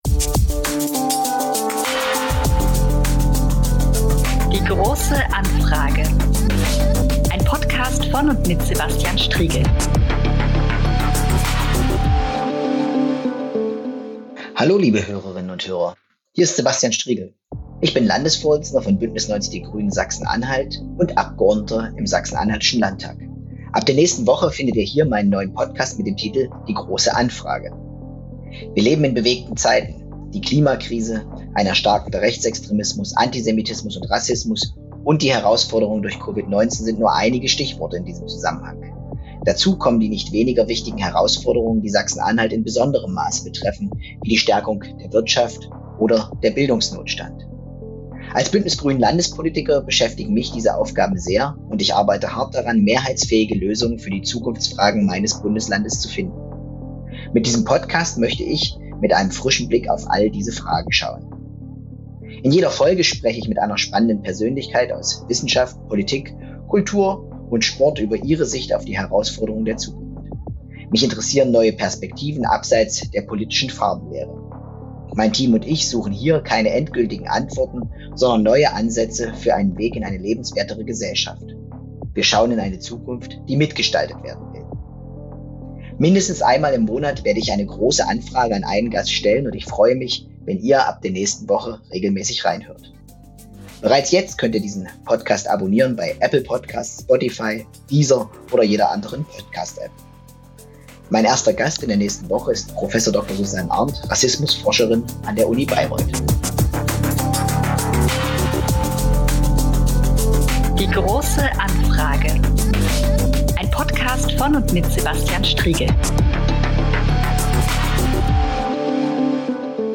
In jeder Podcast-Folge spricht er mit einer spannenden Persönlichkeit aus Wissenschaft, Politik, Kultur und Sport über ihre Sicht auf die Herausforderungen der Zukunft. Wichtig sind dabei neue Perspektiven auf die Herausforderungen unserer Zeit, abseits der politischen Farbenlehre.